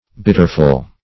Bitterful \Bit"ter*ful\, a.
bitterful.mp3